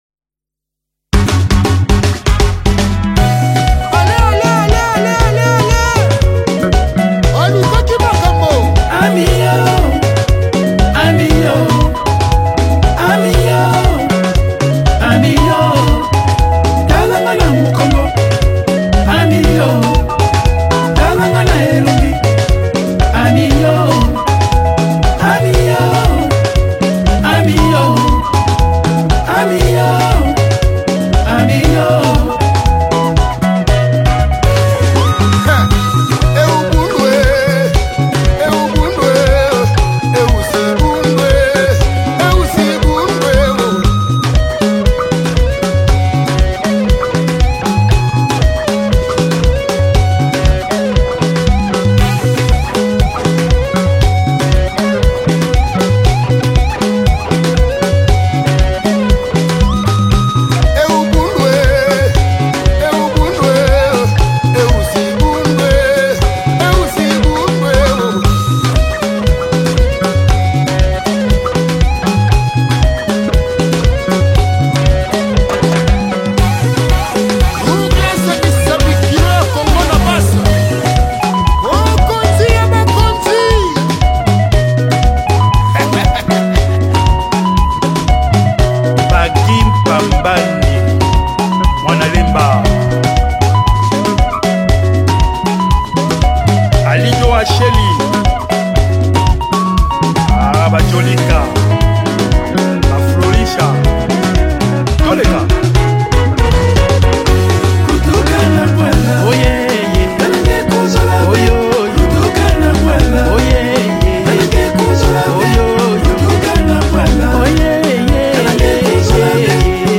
soukous